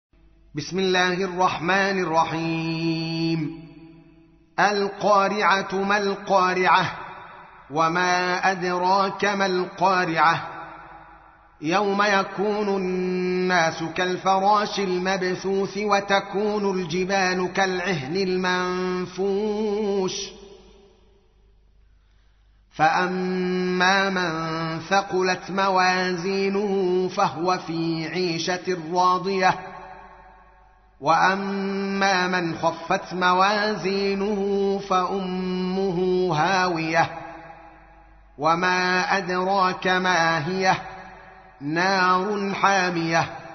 تحميل : 101. سورة القارعة / القارئ الدوكالي محمد العالم / القرآن الكريم / موقع يا حسين